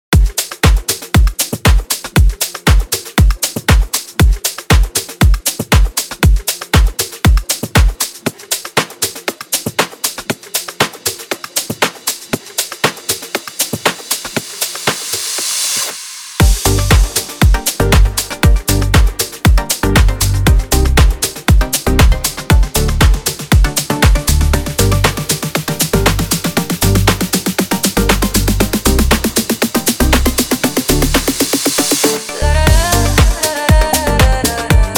Жанр: Электроника